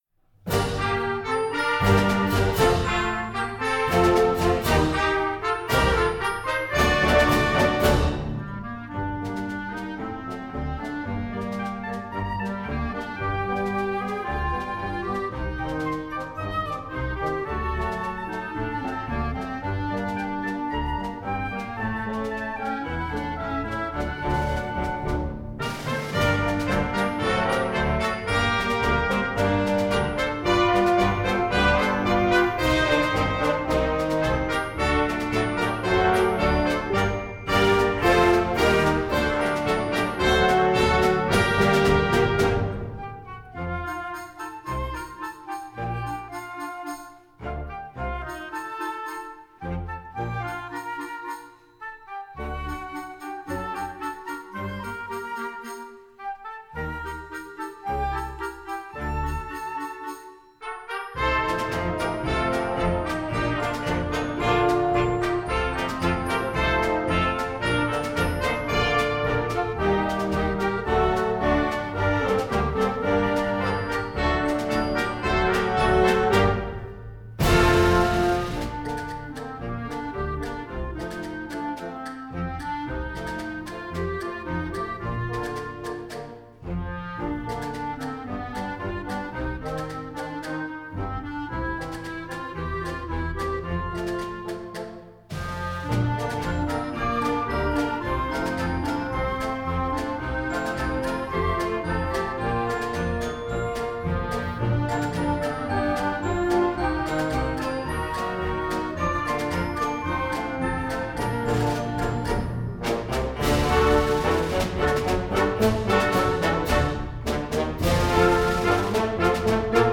Gattung: Paso doble für Jugendblasorchester
Besetzung: Blasorchester